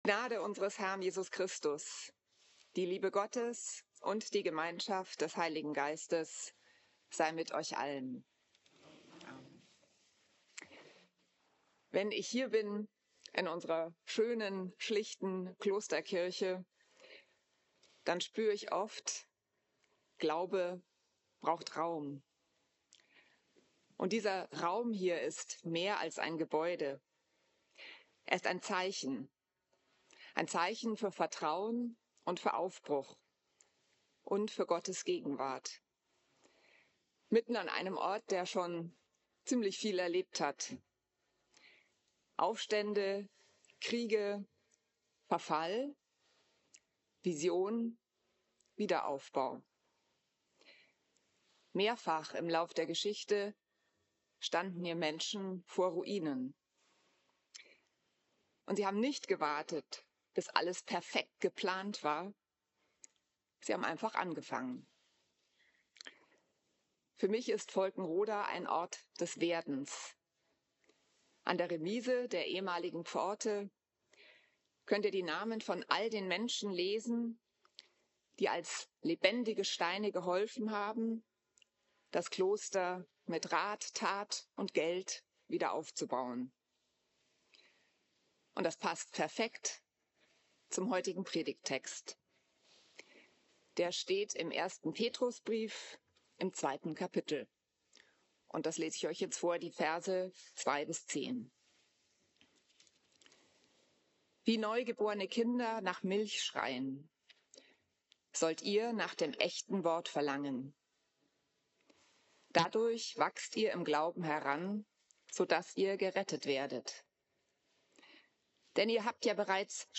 Predigt über 1. Petrus 2,2-10 am 6. Sonntag nach Trinitatis
Klosterkirche Volkenroda, 27.